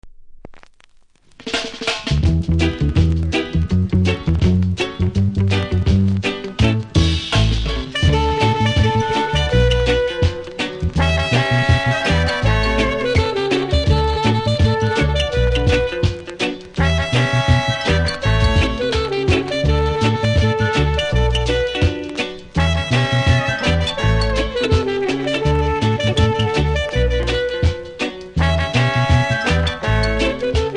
ジュークボックス跡、深めのキズありますが気になるほど影響しておりません。